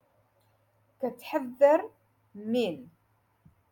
Moroccan Dialect- Rotation Six - Lesson Two Five